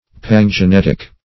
pangenetic - definition of pangenetic - synonyms, pronunciation, spelling from Free Dictionary Search Result for " pangenetic" : The Collaborative International Dictionary of English v.0.48: Pangenetic \Pan`ge*net"ic\, a. (Biol.) Of or pertaining to pangenesis.
pangenetic.mp3